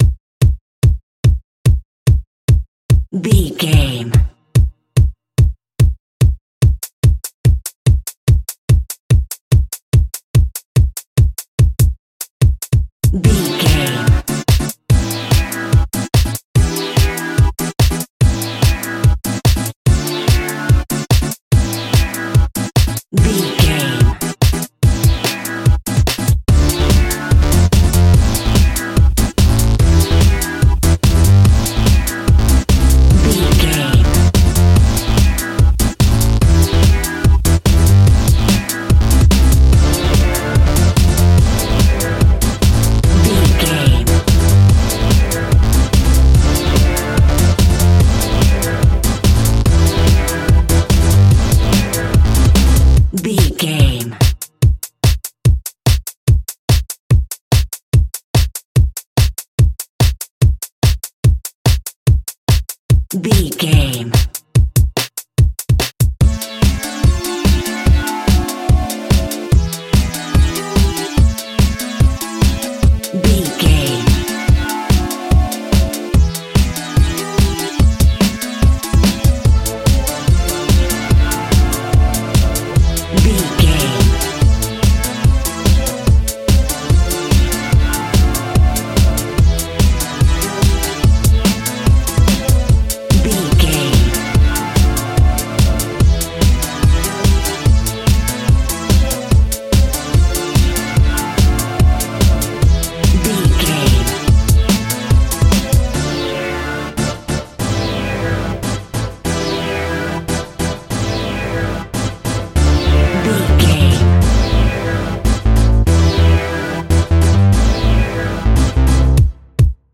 Aeolian/Minor
Fast
energetic
hypnotic
drum machine
synthesiser
acid house
uptempo
synth leads
synth bass